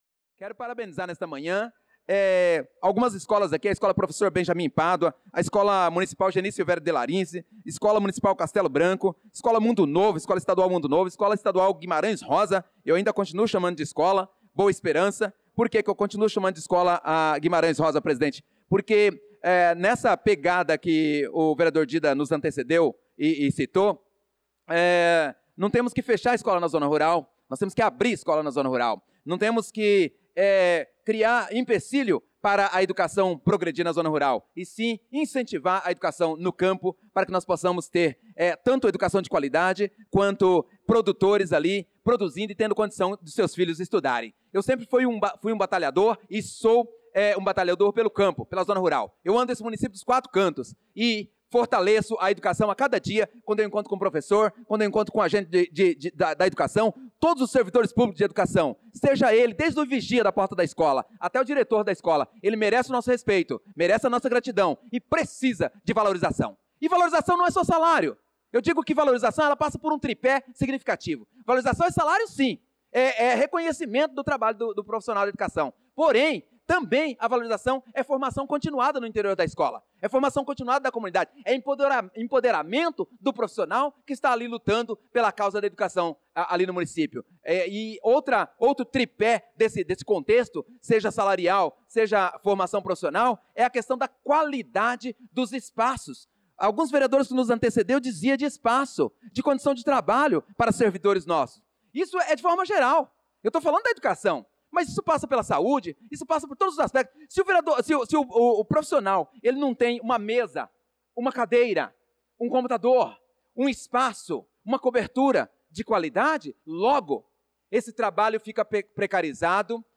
Pronunciamento do vereador Prof. Nilson na Sessão Ordinária do dia 07/07/2025.